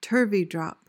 PRONUNCIATION:
(TUHR-vee-drop)